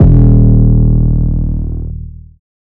808 (BestFriends).wav